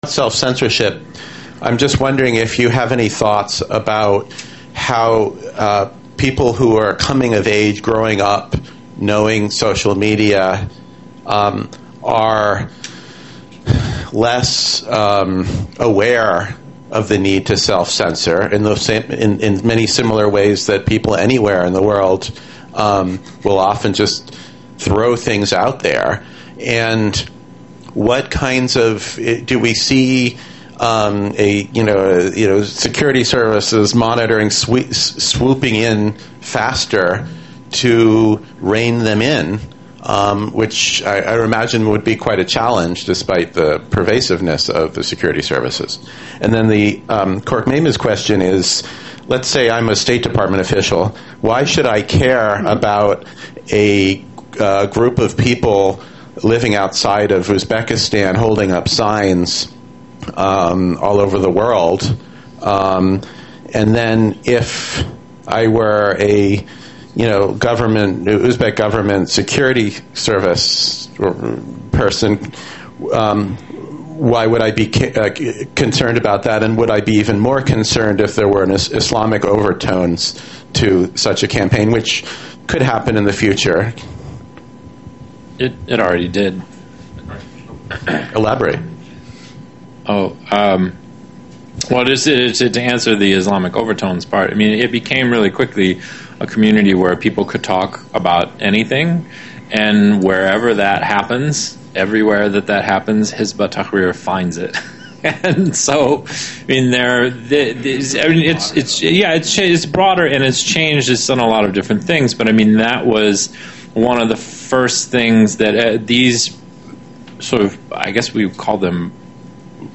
Roundtabe on Digital Islam. A New Marketplace for Religion. GWU, Central Asia Program, Tuesday, Nov 4, 2014. Questions and answers.